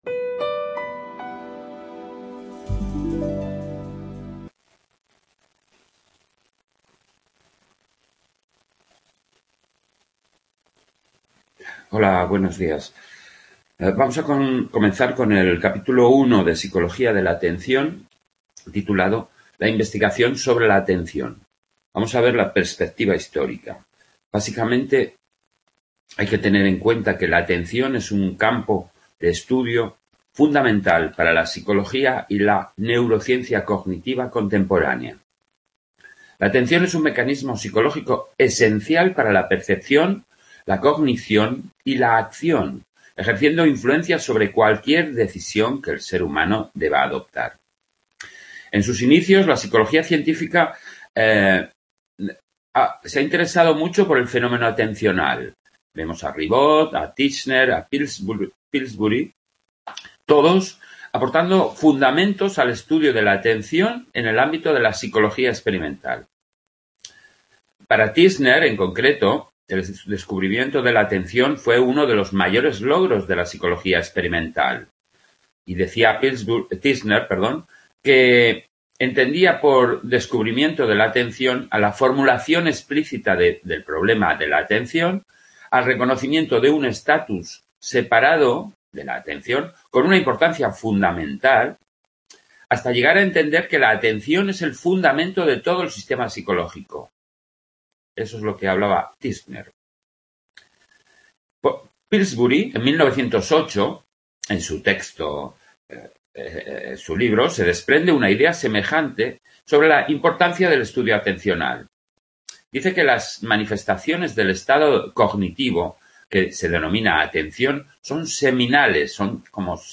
Psicología de la Atención, grabada en el Centro asociado UNED de Sant Boi de Llobregat